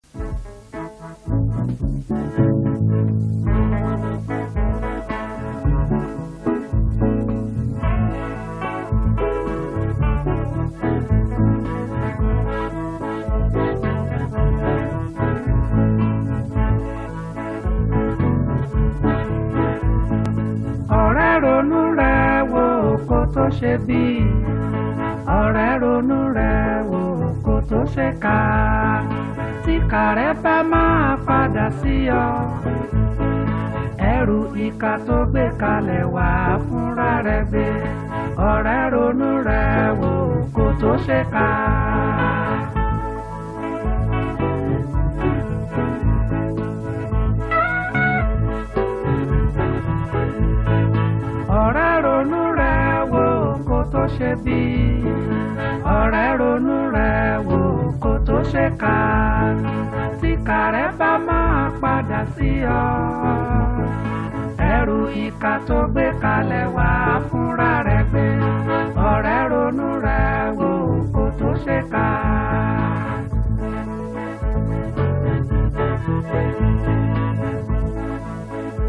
March 28, 2025 Publisher 01 Gospel 0